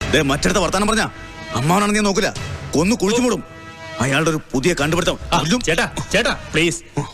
confrontation # angry